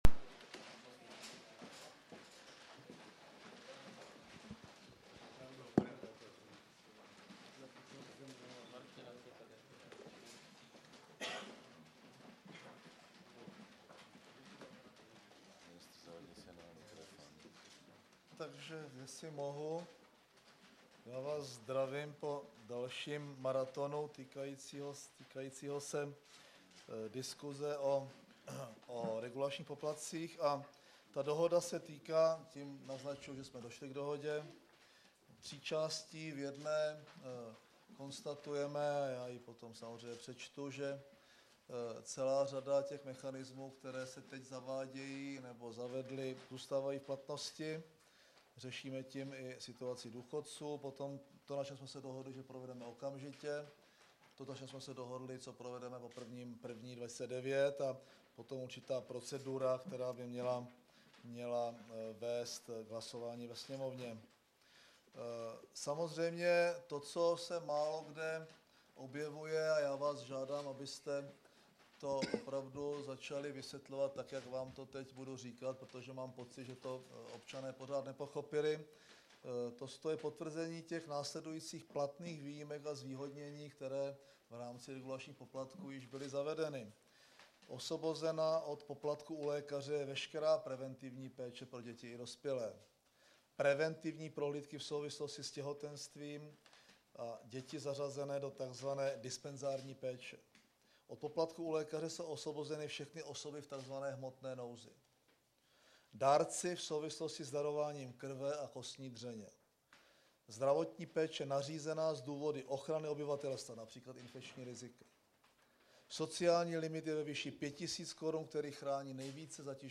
Tisková konference po jednání koaličních stran 2. června 2008 v Kramářově vile - K9